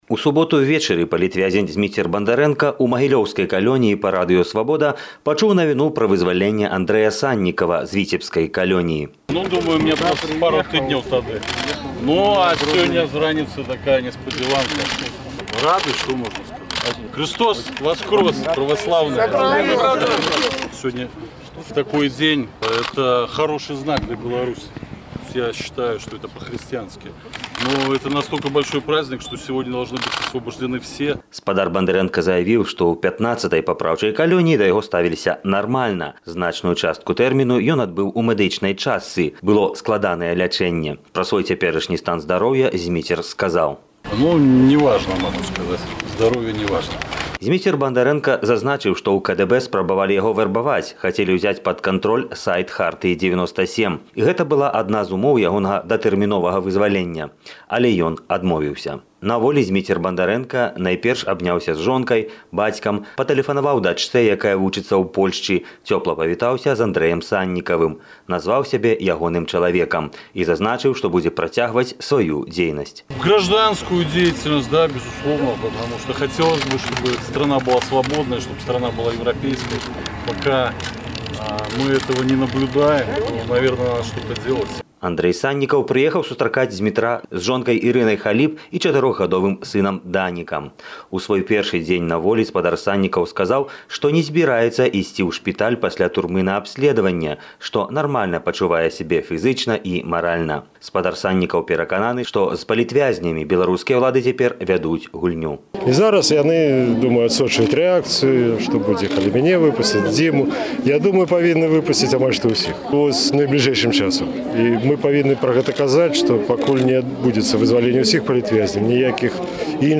Інтэрвію Андрэя Саньнікава Радыё Свабода